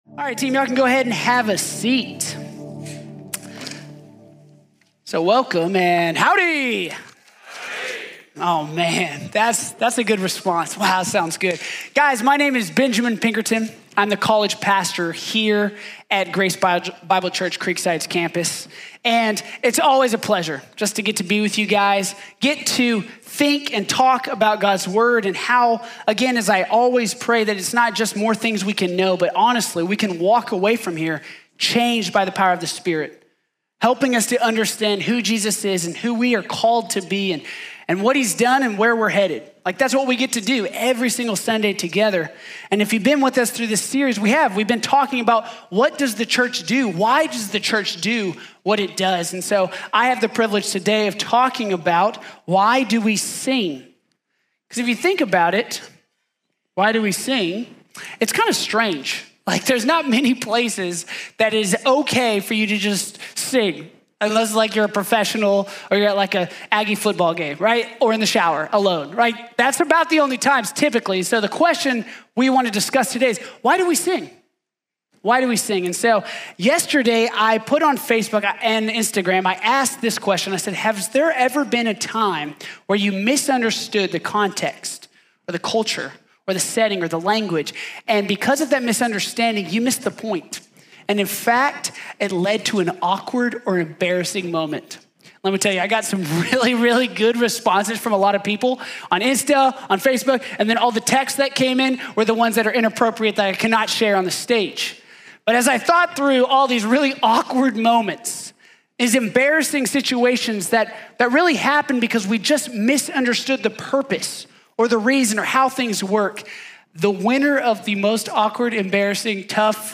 Why Do We Sing | Sermon | Grace Bible Church